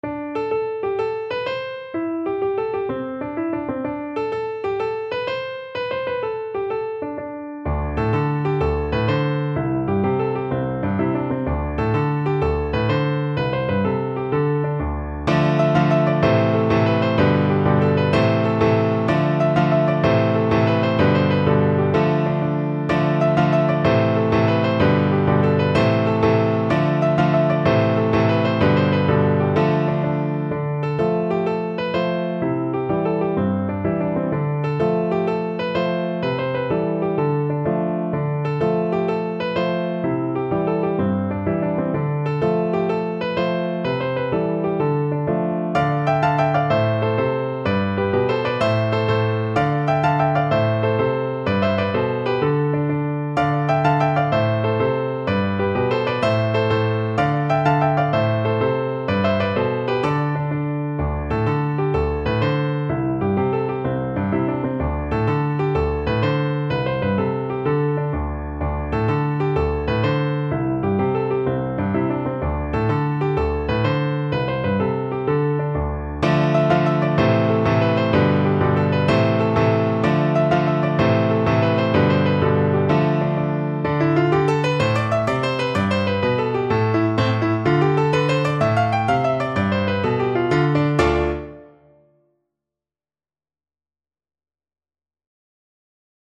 No parts available for this pieces as it is for solo piano.
Traditional Music of unknown author.
A minor (Sounding Pitch) (View more A minor Music for Piano )
12/8 (View more 12/8 Music)
Fast .=c.126
Piano  (View more Intermediate Piano Music)
Irish